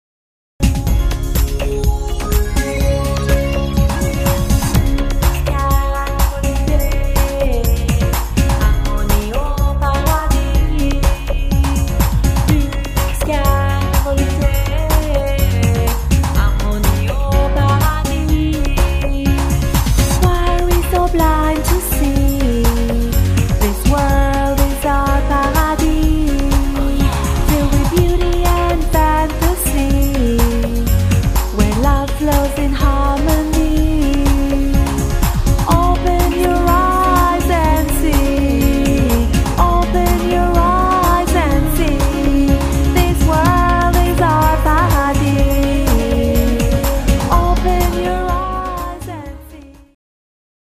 Category: Pop